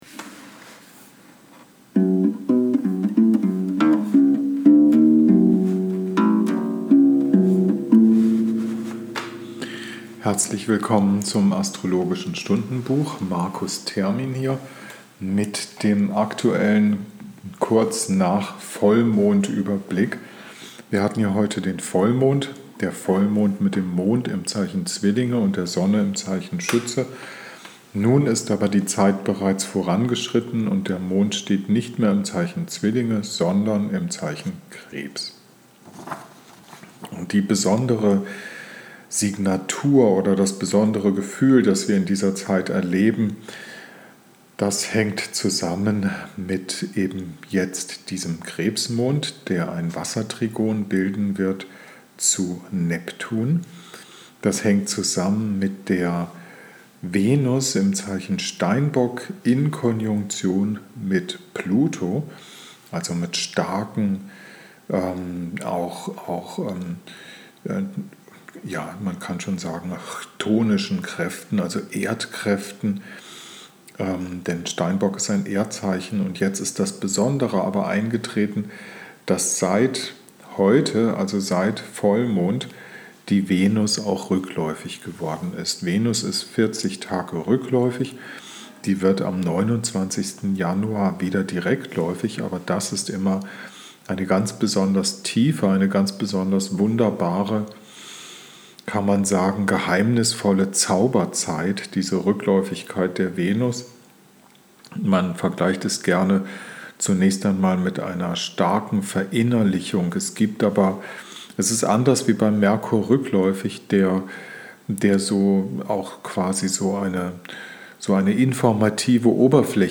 Mundharmonika, Ukulele, Bass, Flute, Voice